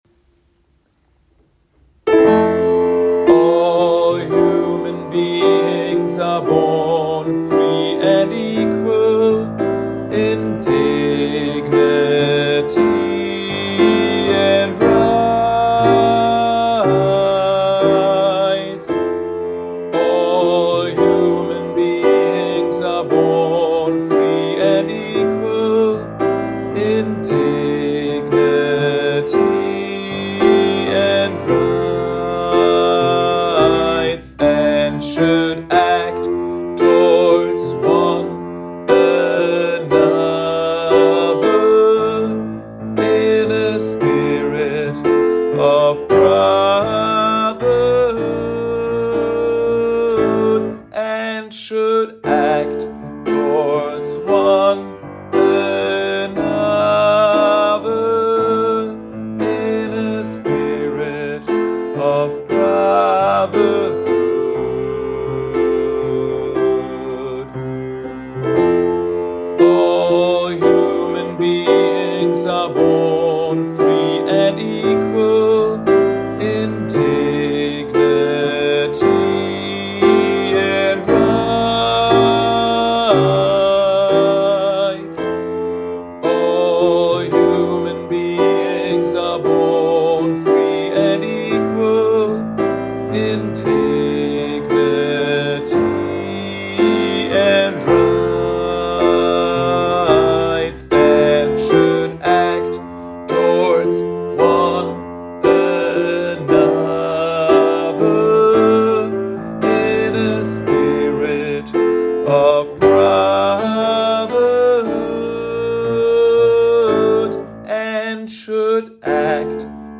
Übe-Dateien
BARITON
Article01_Gospel_B.mp3